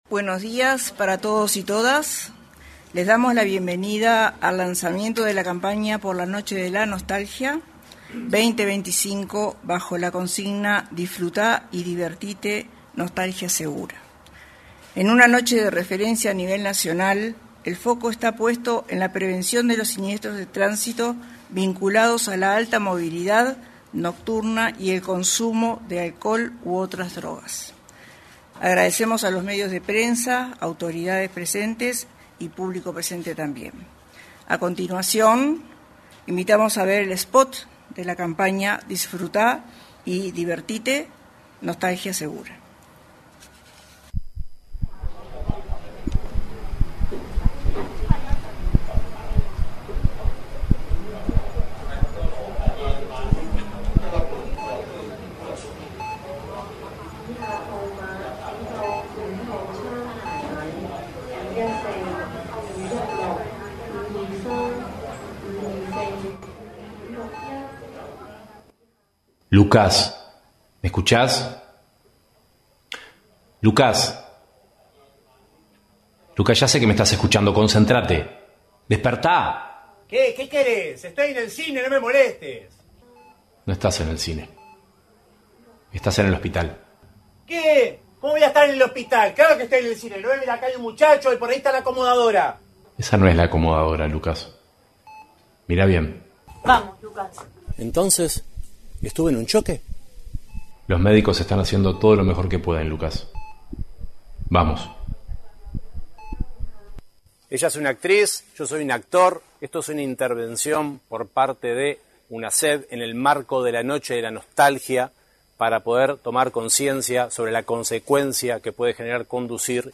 Lanzamiento de campaña por la Noche de la Nostalgia 18/08/2025 Compartir Facebook X Copiar enlace WhatsApp LinkedIn En el lanzamiento de una campaña por la Noche de la Nostalgia, realizado en la sala de prensa de la Torre Ejecutiva, se expresaron el presidente de la Unidad Nacional de Seguridad Vial, Marcelo Metediera; el secretario general de la Junta Nacional de Drogas, Gabriel Rossi, y el director nacional de Policía Caminera, Luis Calzada.